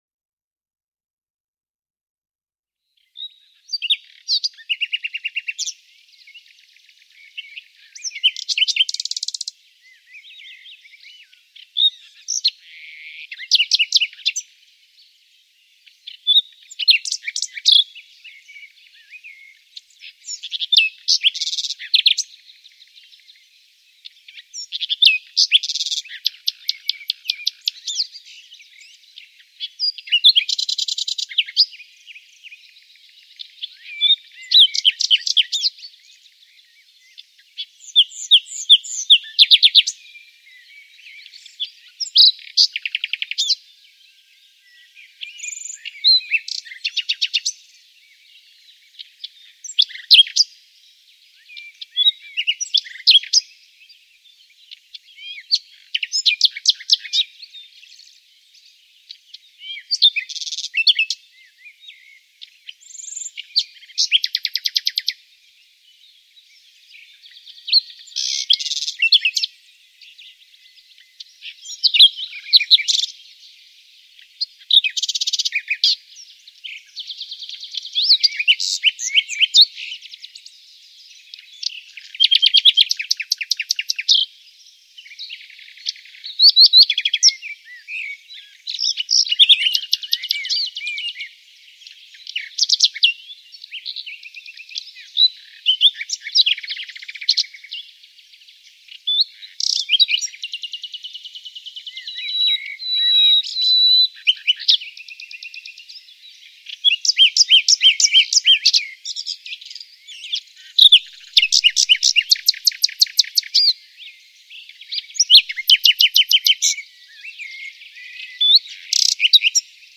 Zur Brutzeit lässt das Männchen sogar seinen Gesang zur Nachtzeit hören, deshalb auch der Name.
Nachtigall(.mp3) und Sprosser